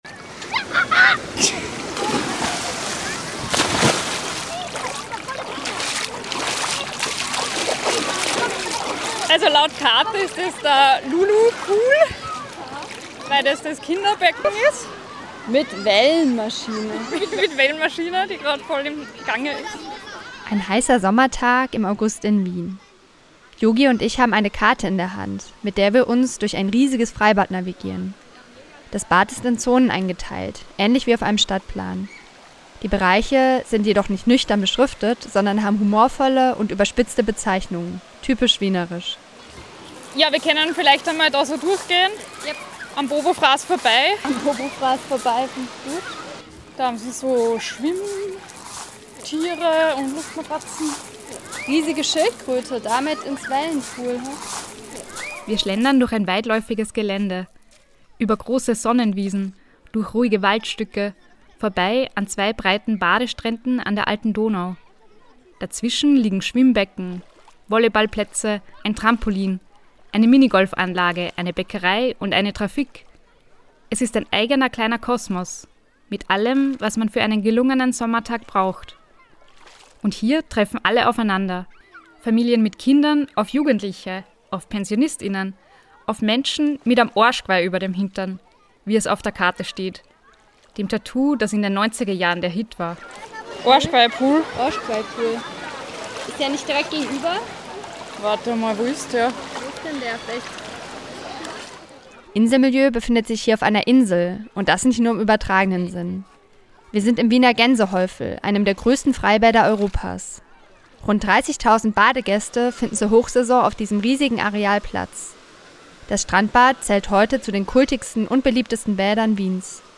Diese Folge führt uns auf eine Reportage-Reise durch verschiedene Wiener Freibäder: zur Kabanen Gemeinschaft ins Gänsehäufel, in den FKK-Bereich, zur Demokratiefitness auf der Liegewiese und schließlich hinein in die Fluten des Donaukanals. Zwischen Sprungturm, Rutsche und Wellenbecken fragen wir: Geht die Demokratie gerade baden?